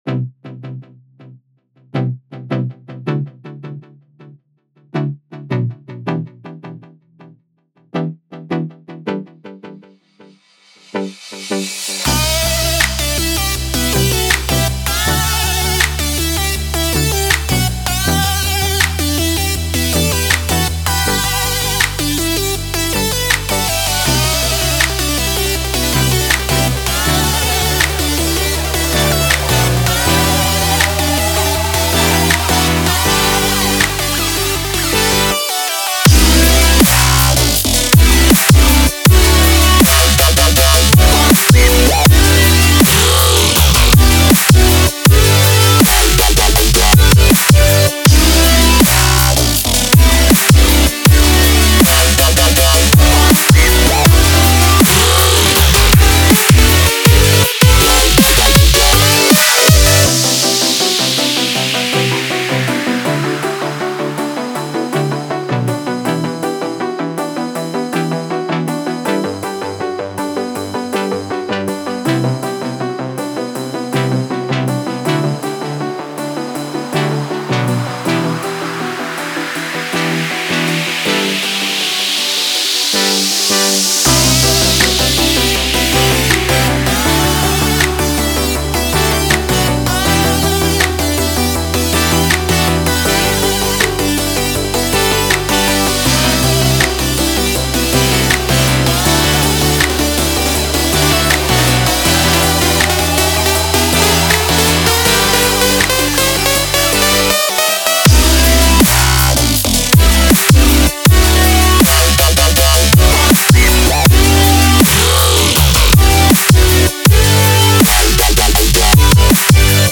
DubstepEDMHouse